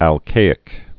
(ăl-kāĭk)